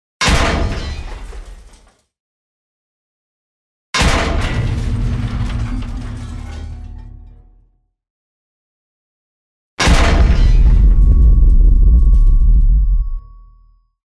Sounds of the game World of tanks, download and listen online
• Quality: High